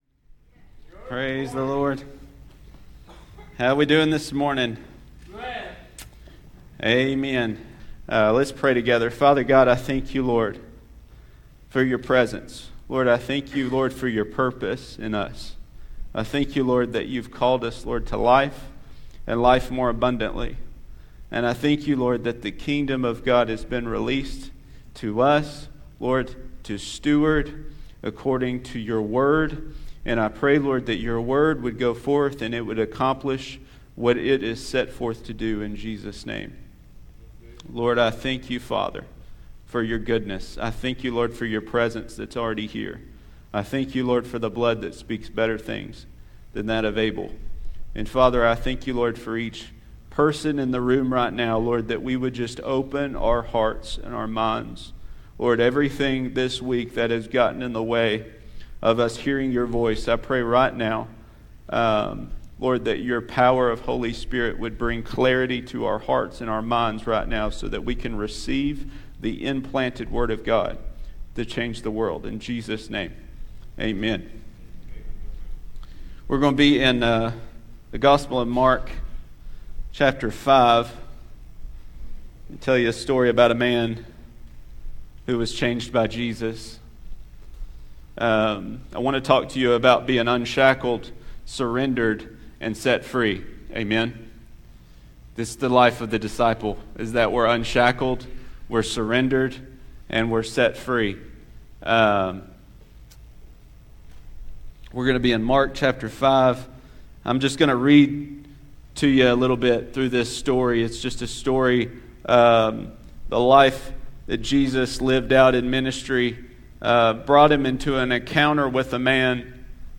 Sermons | Living for the Brand Cowboy Church of Athens